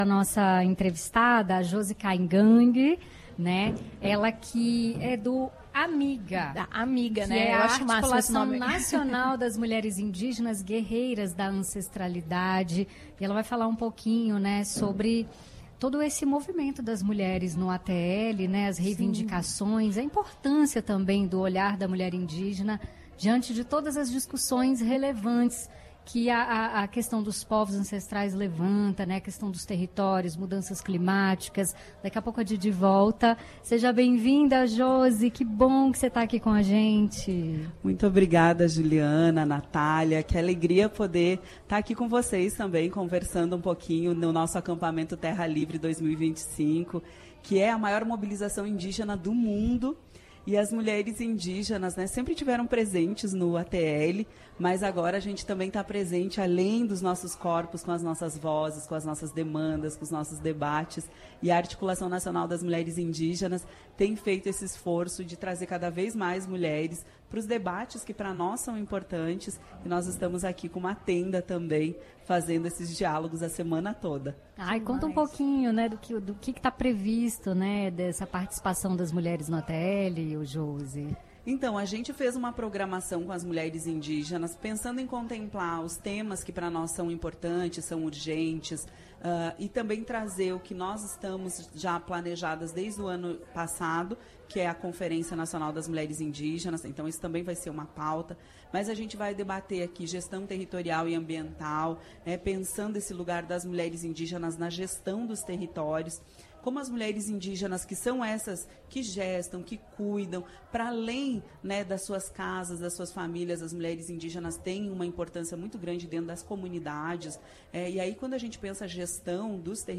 Entrevista no Acampamento Terra Livre: conheça as reinvindicações das mulheres indígenas
A entrevista foi feita durante a 21ª edição do Acampamento Terra Livre 2025, em estúdio montado dentro do acampamento, localizado no centro da capital federal.